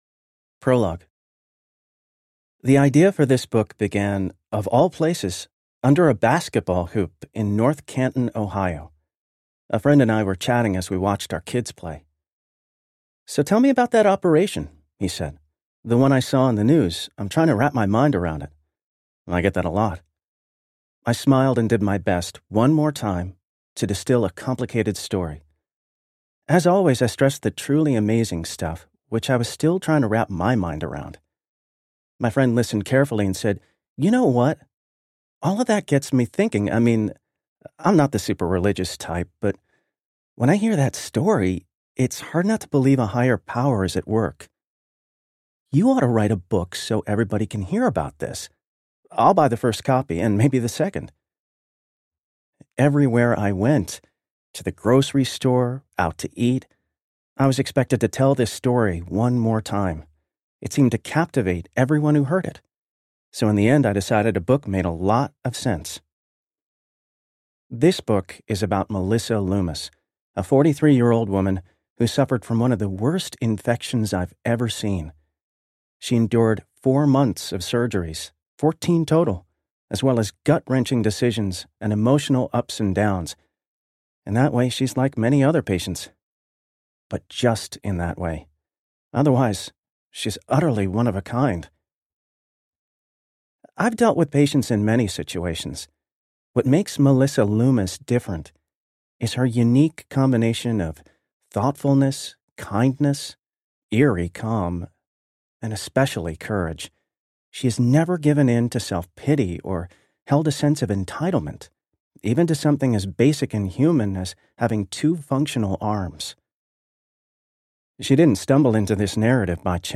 Rewired Audiobook
Narrator
5.4 Hrs. – Unabridged